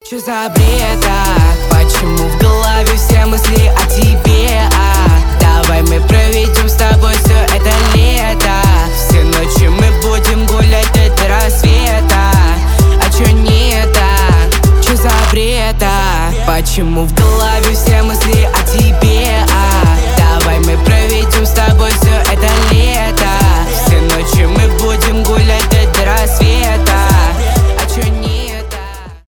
поп
рэп